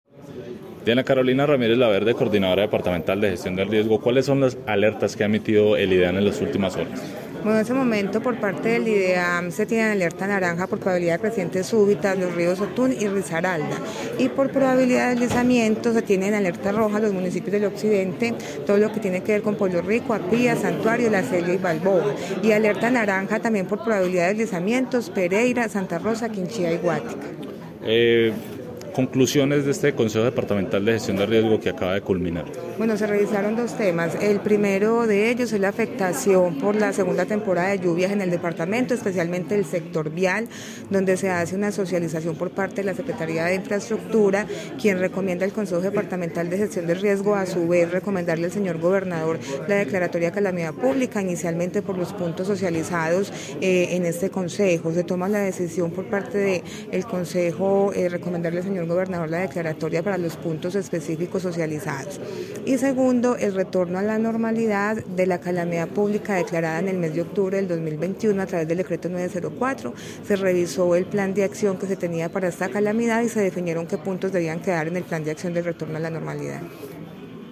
Diana-Carolina-Ramirez-Laverde-Coordinadora-Departamental-de-Gestion-del-Riesgo-de-Desastres.mp3